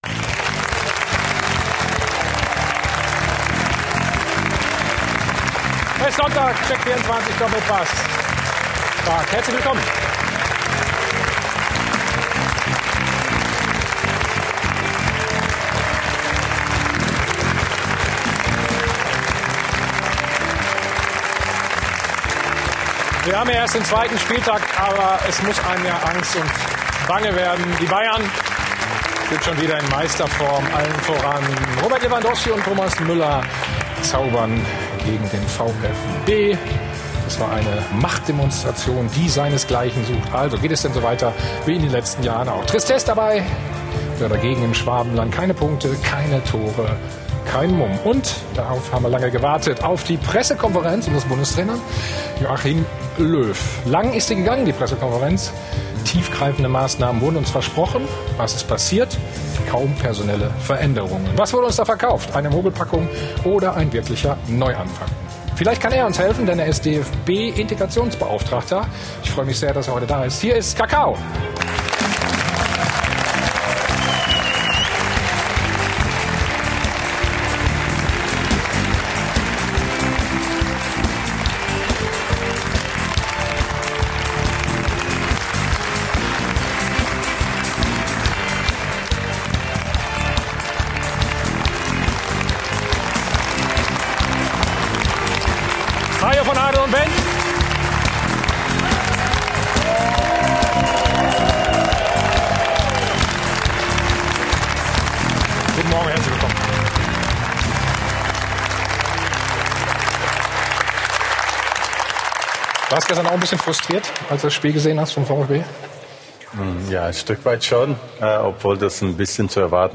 Für alle, die den „Stahlwerk Doppelpass“ verpasst haben: Hier gibt es die kompletten Folgen des berühmten SPORT1 Fußball-Talks als Podcast zum Nachhören. Freut euch auf wöchentliche Diskussionen rund um die Bundesliga und leidenschaftliche Fußball-Debatten mit den wichtigsten Akteuren im deutschen Fußball, führenden Journalisten und den SPORT1-Experten.